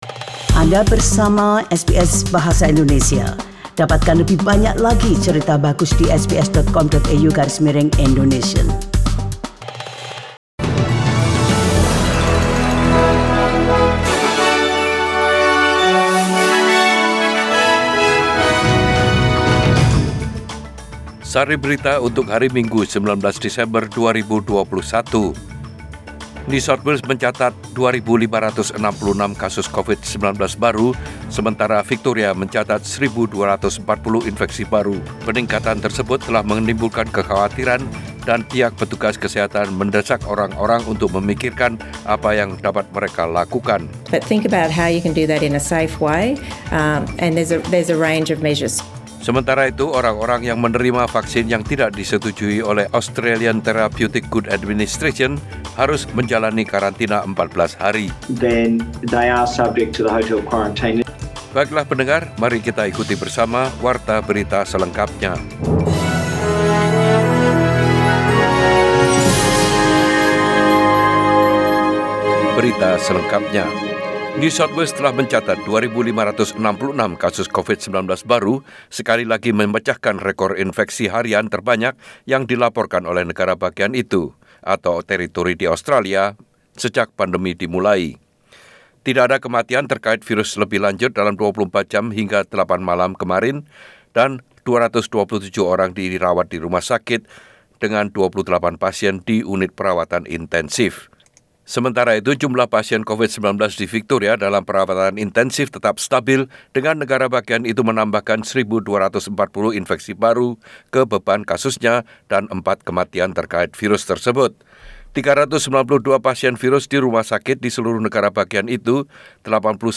SBS Radio News in Bahasa Indonesia - 19 December 2021
Warta Berita Radio SBS Program Bahasa Indonesia.